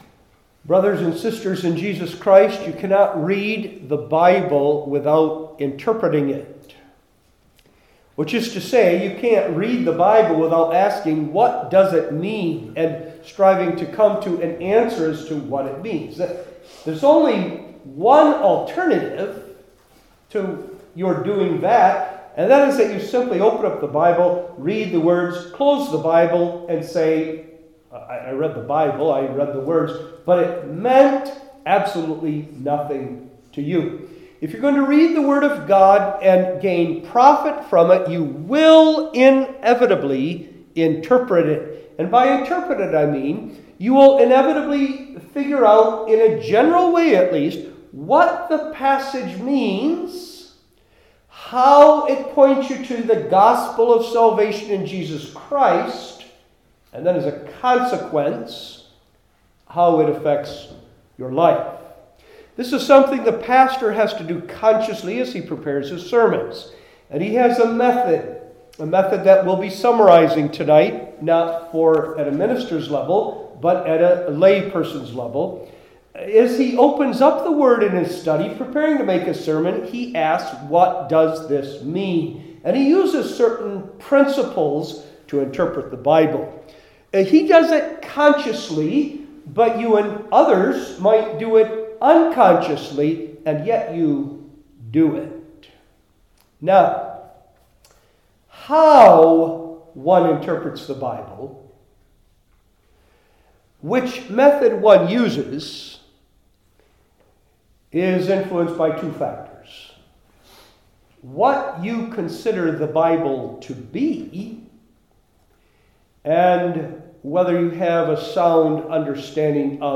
Service Type: Lectures/Debates/Interviews